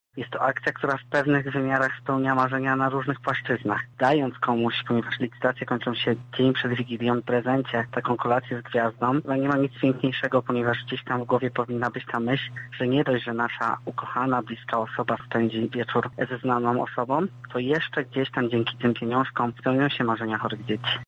O tym dlaczego warto pomagać mówi wolontariusz